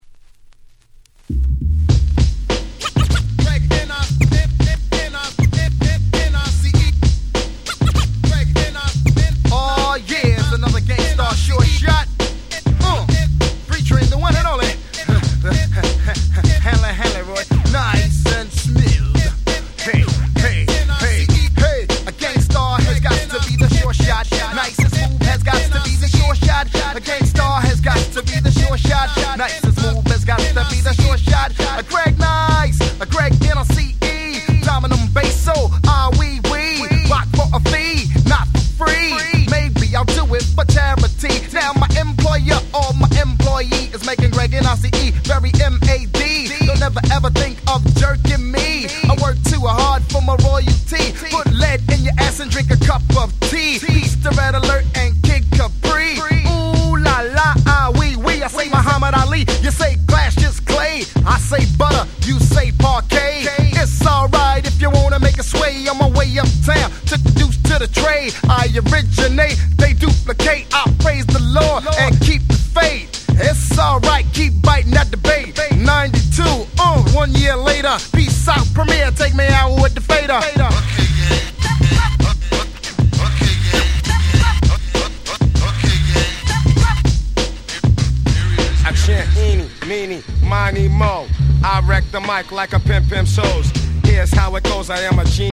92' Super Hip Hop Classics !!
ギャングスター プリモ プレミア ナイスアンドスムース 90's Boom Bap ブーンバップ